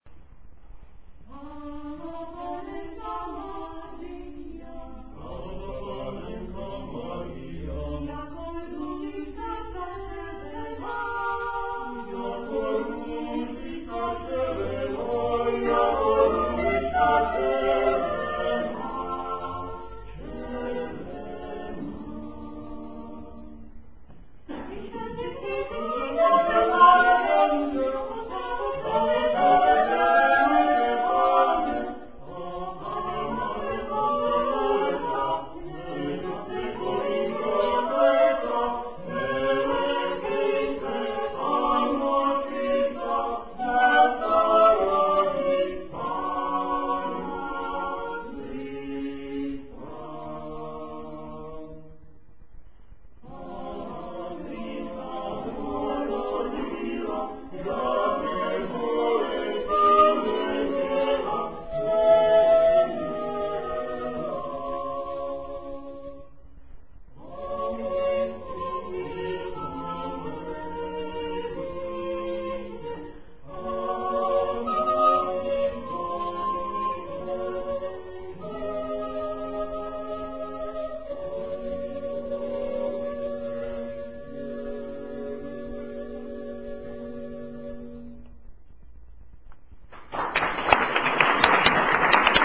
18. komorní koncert na radnici v Modřicích
Vokální kvintet a Smíšený pěvecký sbor města Modřice - 14. prosince 2005
Ukázkové amatérské nahrávky ve formátu WMA: